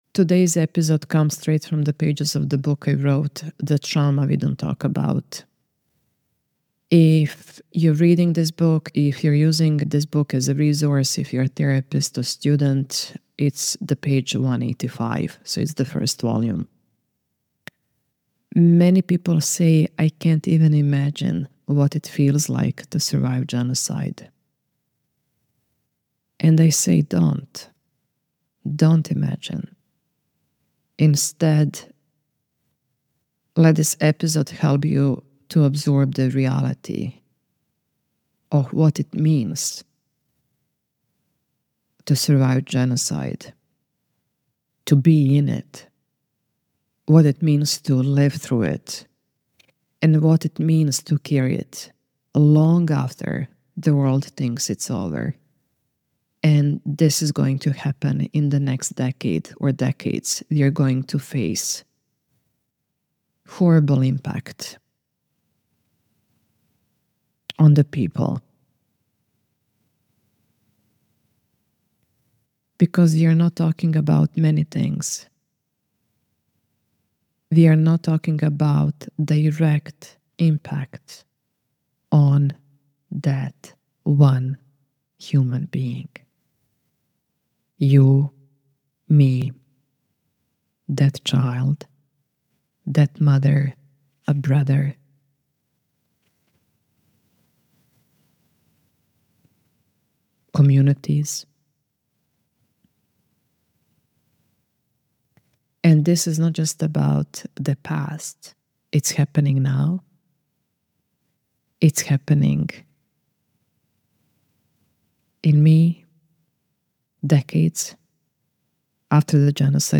This is a reading and reflection—not from the past, but from the living, ongoing truth of what genocide does to the body, the nervous system, and the identity.